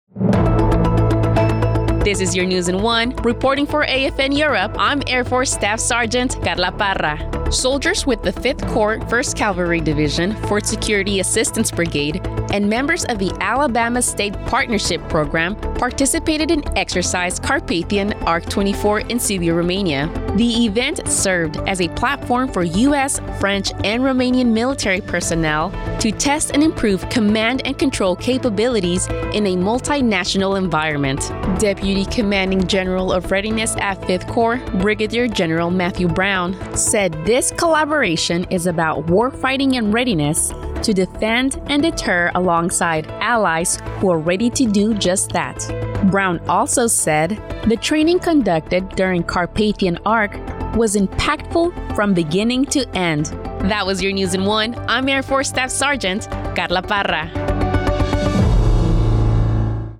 1st Cavstronger togetherV-CorpsNews in OneArmy